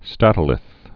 (stătl-ĭth)